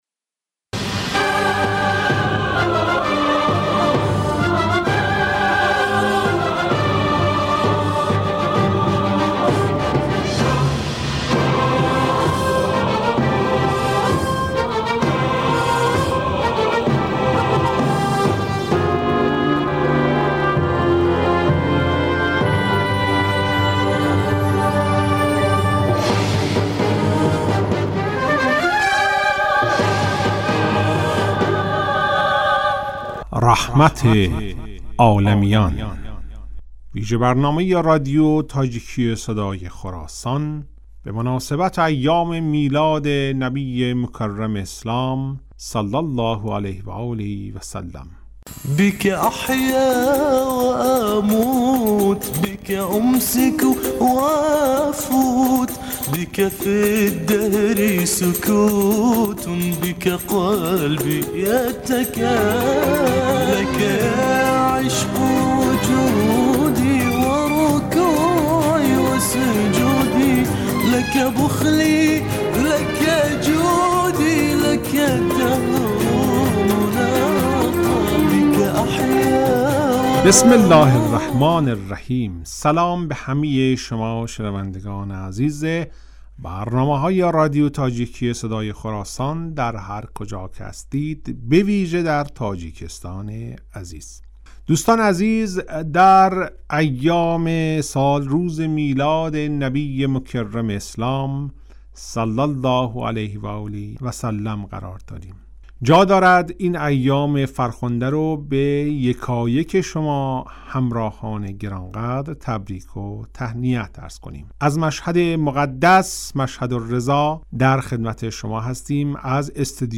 "رحمت عالمیان" ویژه برنامه ای است که به مناسبت ولادت پیامبر گرامی اسلام حضرت محمد مصطفی(ص) و آغاز هفته وحدت در رادیو تاجیکی تهیه شده است.